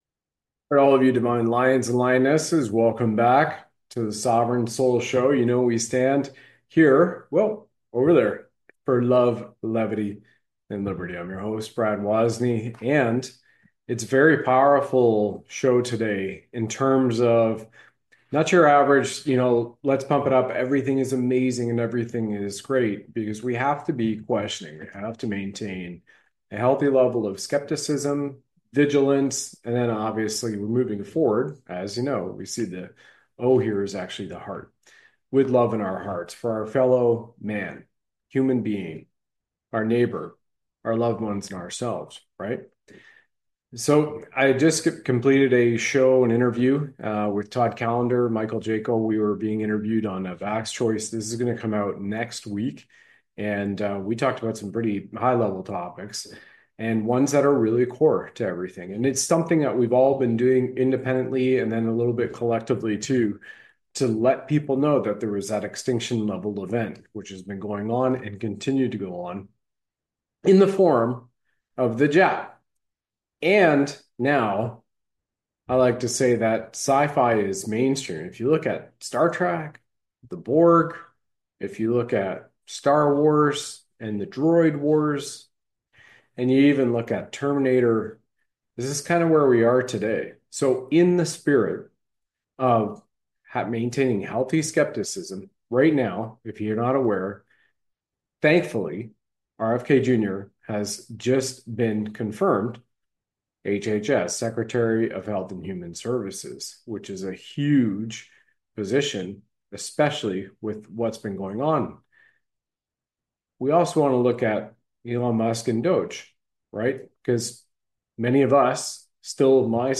Live Shows